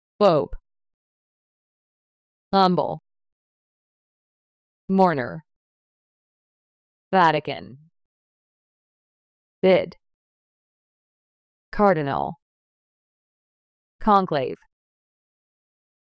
音声を再生し、強勢のある母音（＝大きな赤文字）を意識しながら次の手順で練習しましょう。
pope /poʊp/（名）ローマ教皇
humble /ˈhʌmbəl/（形）謙虚な、控えめな
mourner /ˈmɔrnər/（名）弔問客、悲しんでいる人
conclave /ˈkɑnkleɪv/（名）コンクラーベ（ローマ教皇選出のための秘密会議）